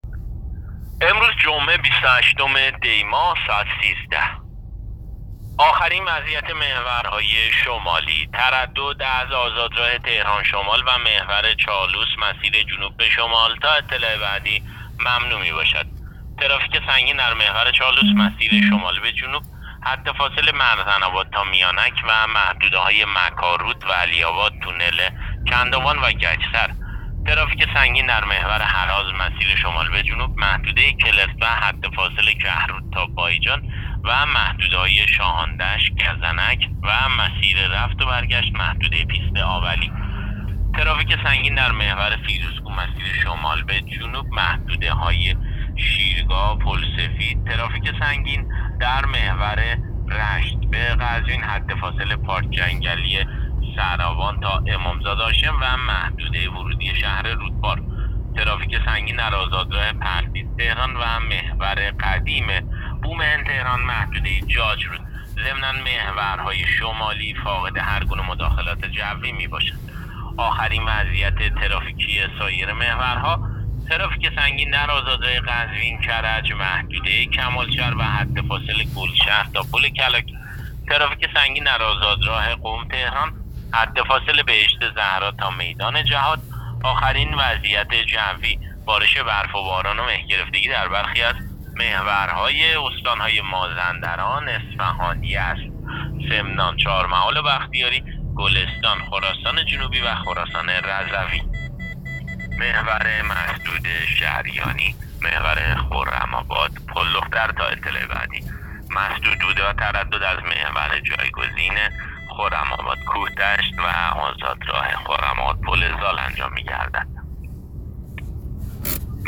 گزارش رادیو اینترنتی از آخرین وضعیت ترافیکی جاده‌ها تا ساعت ۱۳ بیست و هشتم دی؛